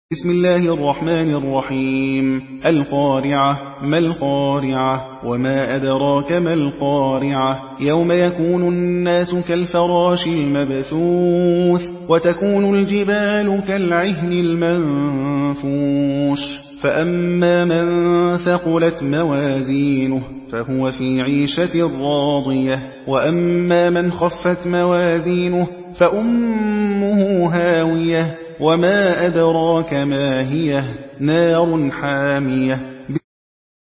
حدر